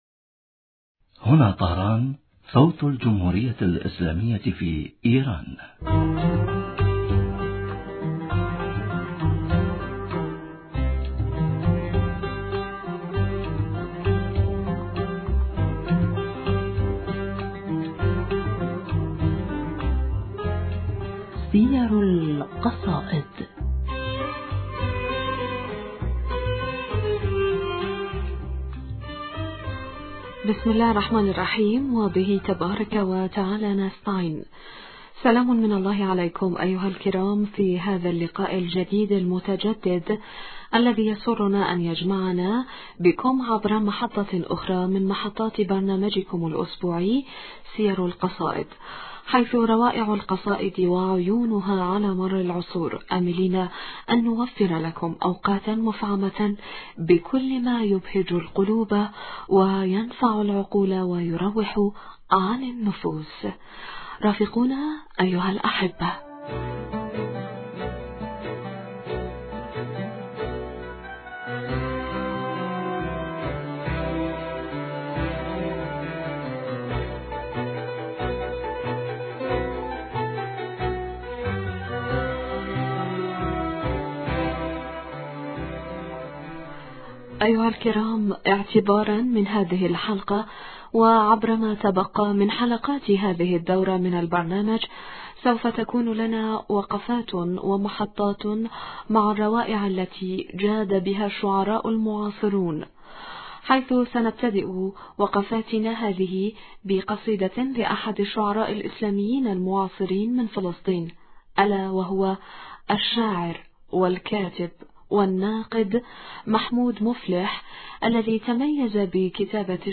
الذي شرفنا بحضوره في الستوديو